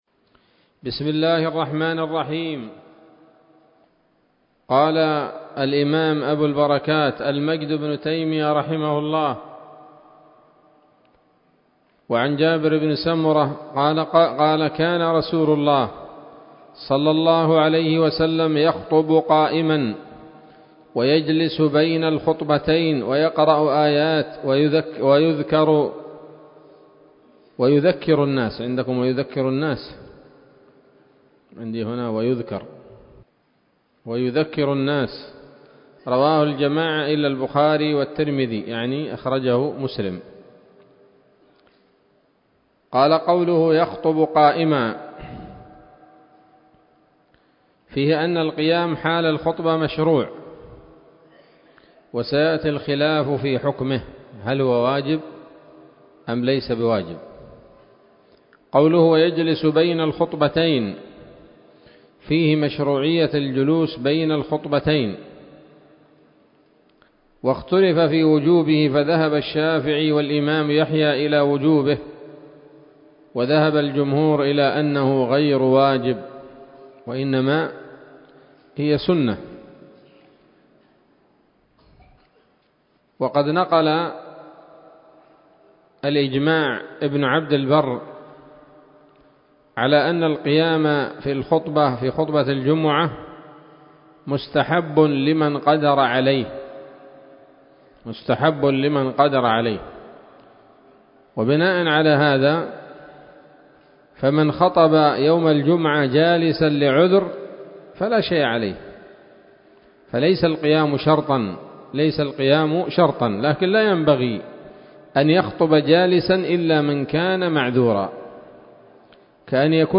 الدرس السابع والعشرون من ‌‌‌‌أَبْوَاب الجمعة من نيل الأوطار